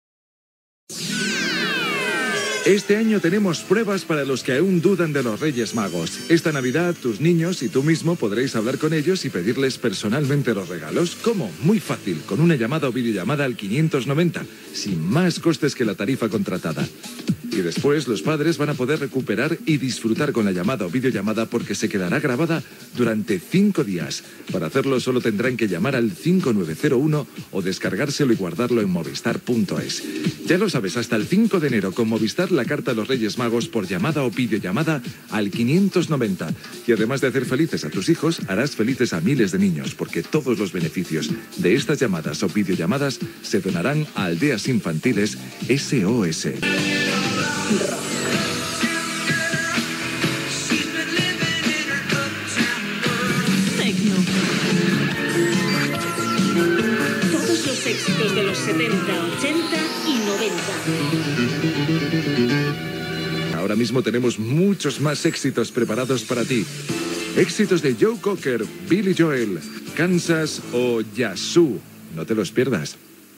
Publicitat, indicatiu i tema musical